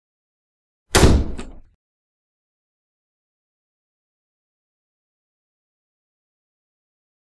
Door Slam #1 Sound Effect